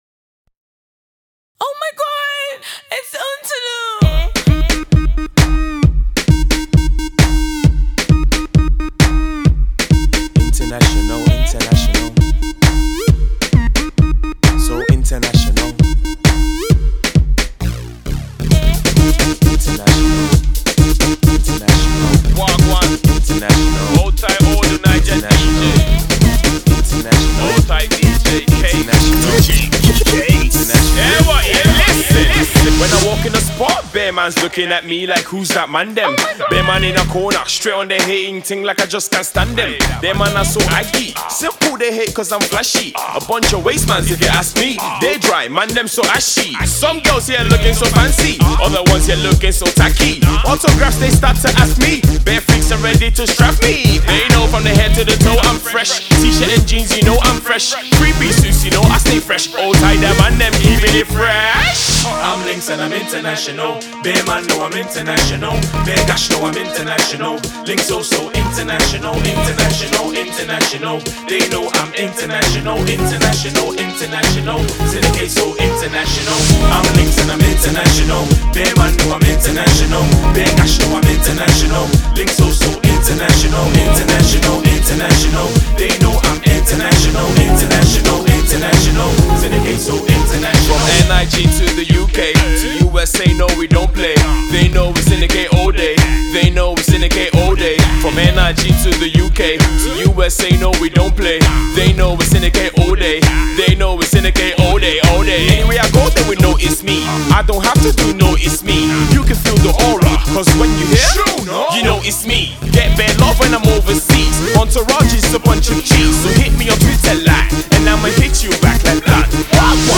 rising rapper
official funky tune!
Love the genre mash-up!